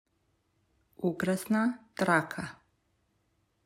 3. Ukrasna traka (click to hear the pronunciation)